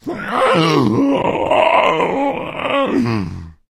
fracture_attack_6.ogg